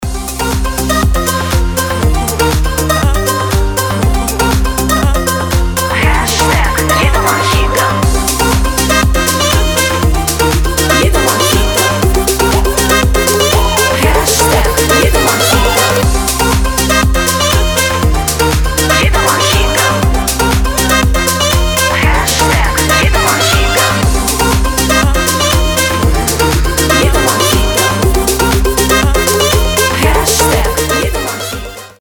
• Качество: 320, Stereo
громкие
заводные
Dance Pop
бодрые